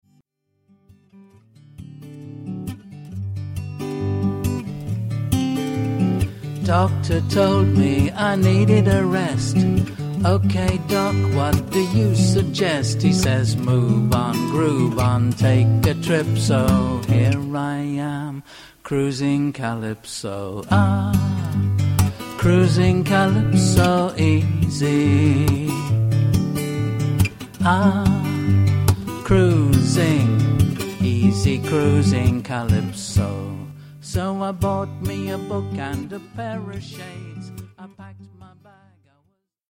lyricist vocalist humorist guitarist
A seafaring song for our time.